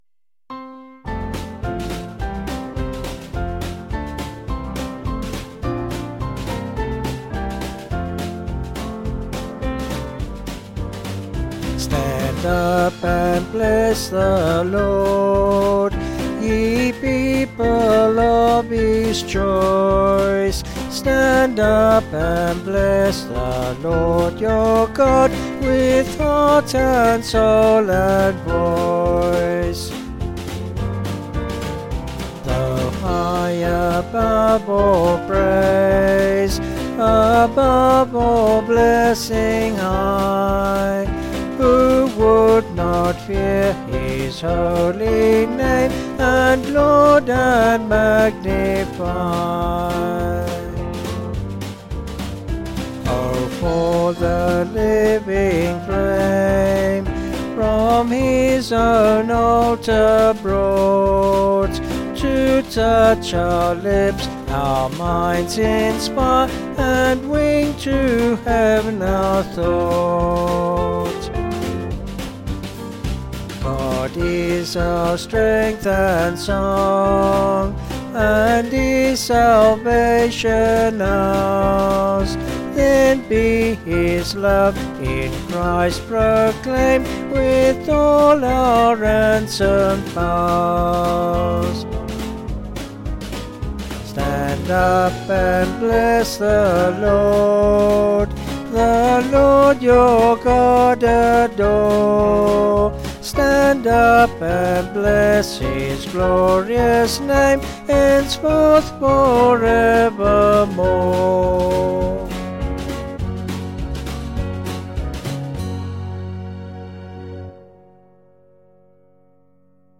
Vocals and Band   264.2kb Sung Lyrics